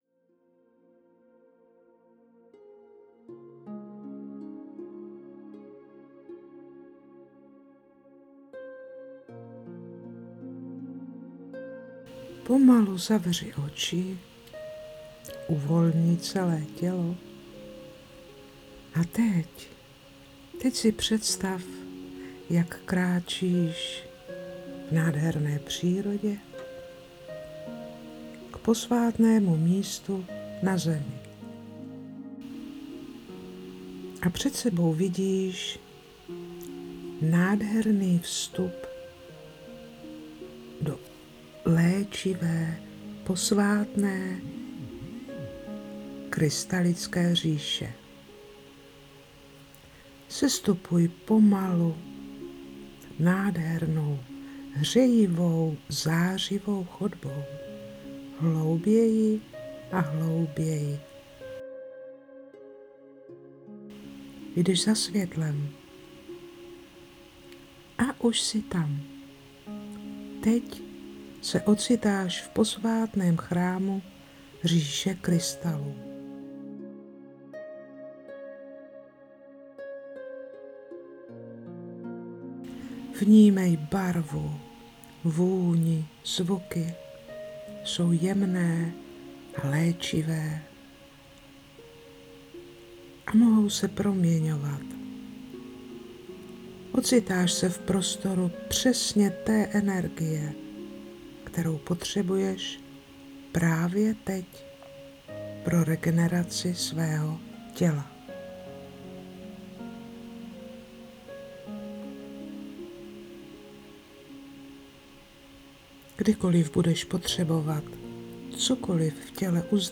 1. adventní neděle – 1. den – 1. meditace